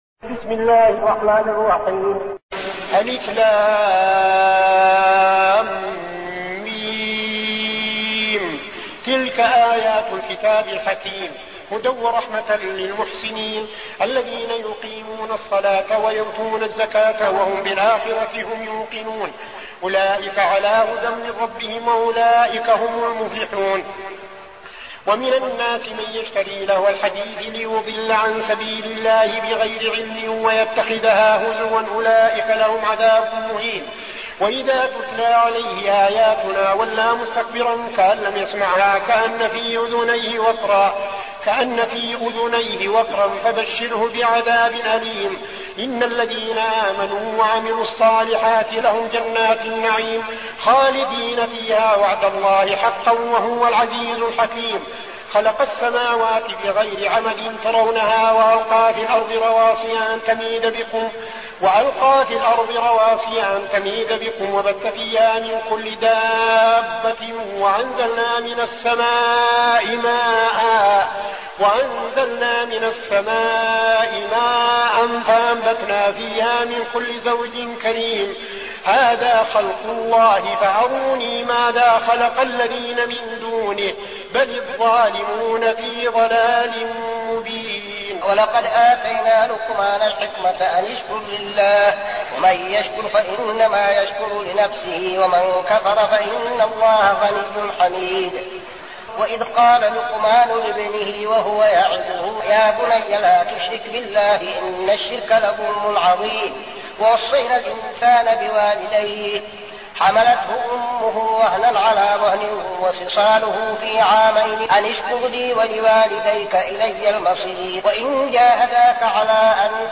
المكان: المسجد النبوي الشيخ: فضيلة الشيخ عبدالعزيز بن صالح فضيلة الشيخ عبدالعزيز بن صالح لقمان